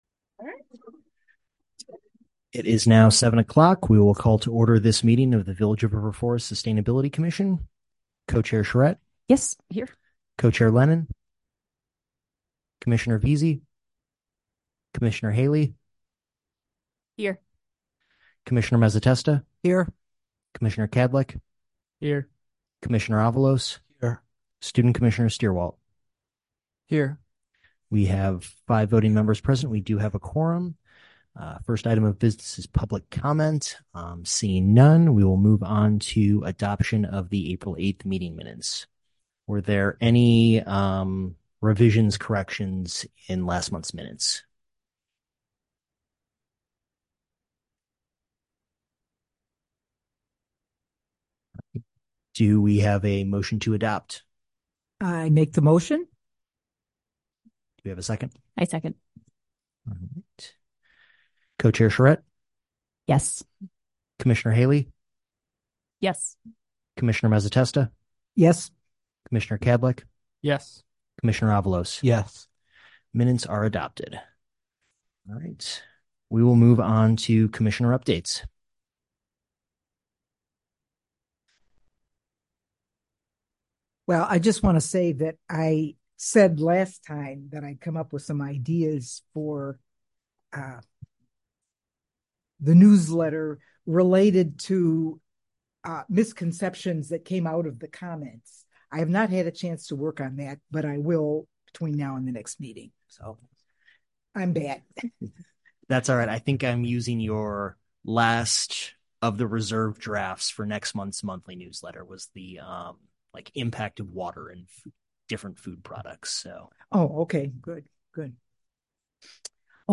Sustainability Commission Meeting
Village Hall - 400 Park Avenue - River Forest - IL - COMMUNITY ROOM